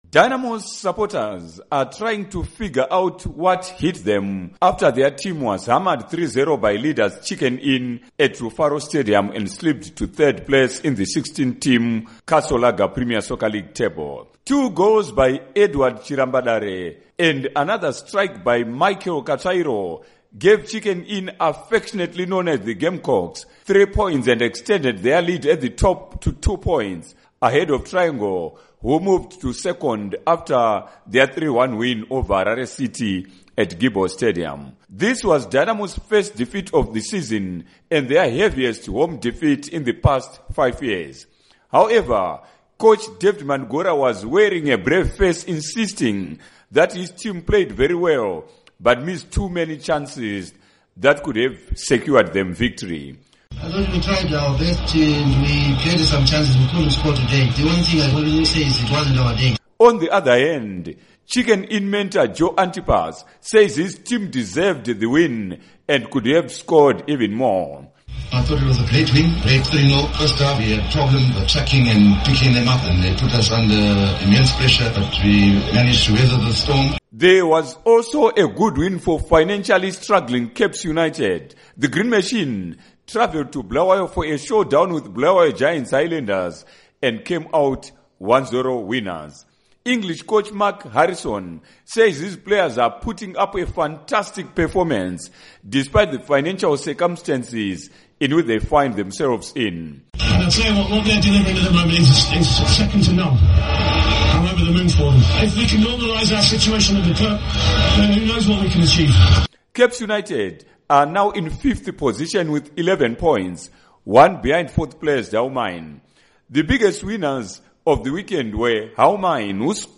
Premier Soccer League Report